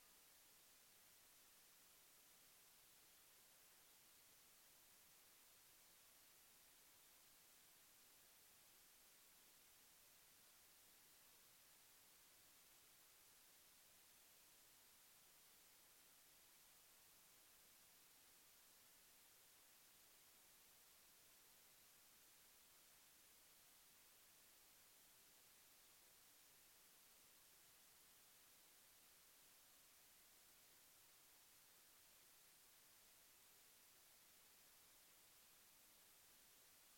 描述：基于风铃样本的闪光铃音序列，包括背景中强烈处理过的谐波层声音。
标签： 效果 和谐 风风铃
声道立体声